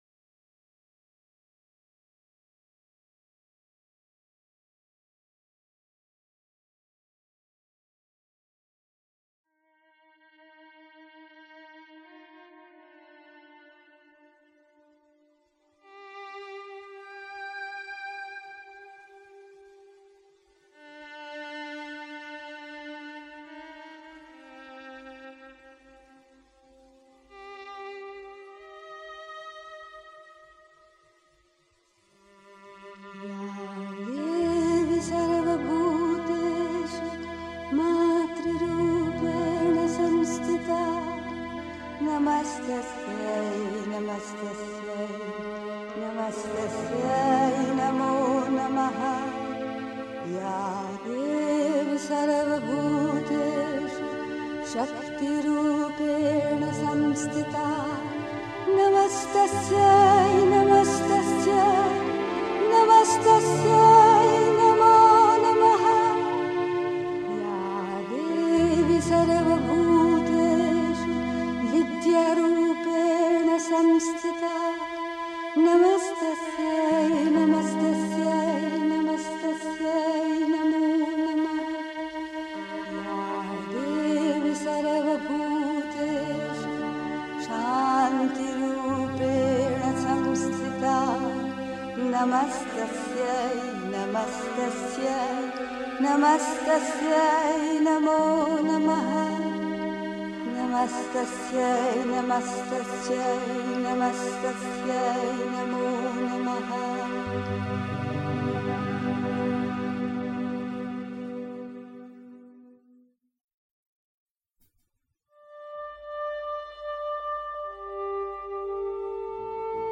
Nicht über spirituelle Erfahrungen sprechen (Die Mutter, White Roses, 16 April 1964) 3. Zwölf Minuten Stille.